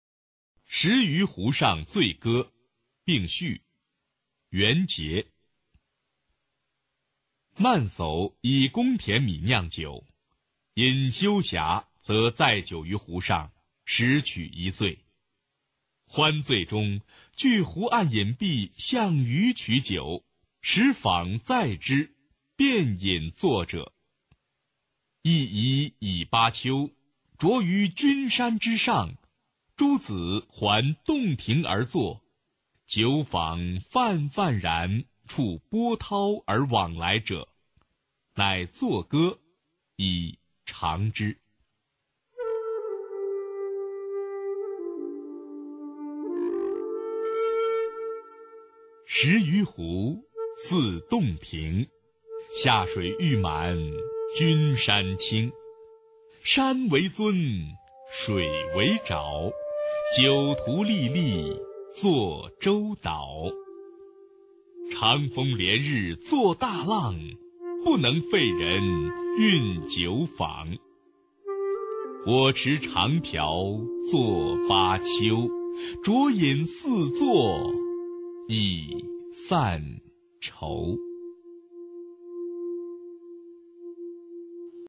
[隋唐诗词诵读]元结-石鱼湖上醉歌并序 配乐诗朗诵